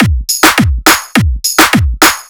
104 BPM Beat Loops Download